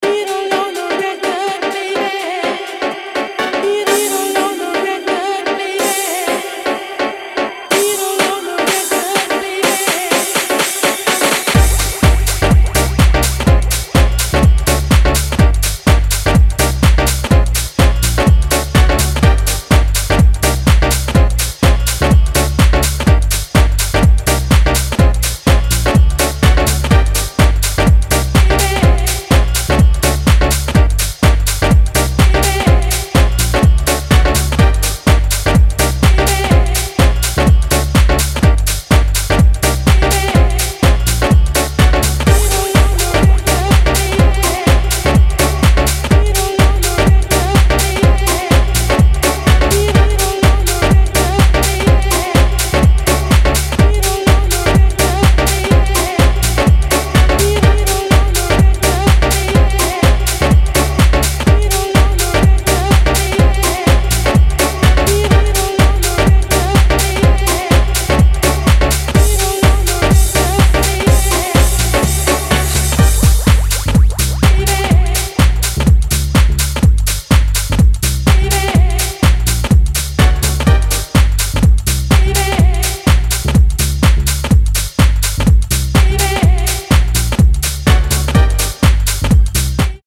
クラシカルでオーセンティック、そしてソウルフルなハウスのフィーリングをモダンに昇華する